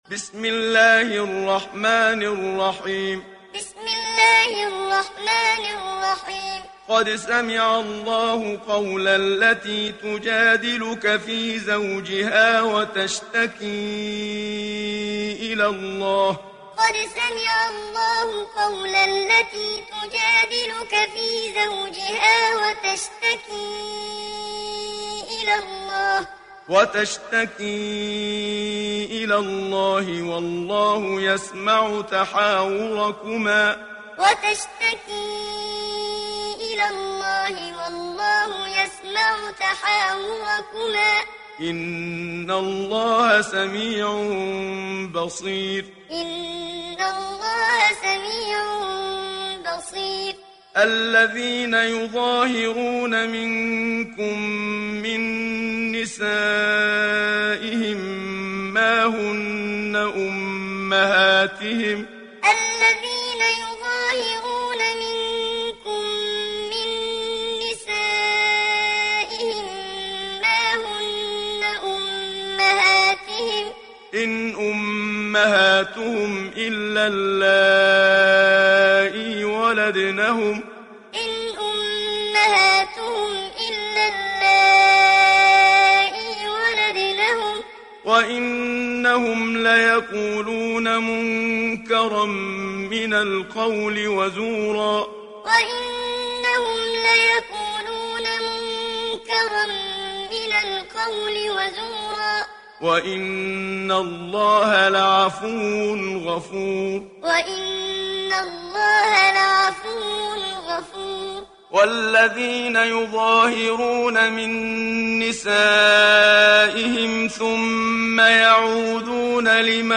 ডাউনলোড সূরা আল-মুজাদালাহ্ Muhammad Siddiq Minshawi Muallim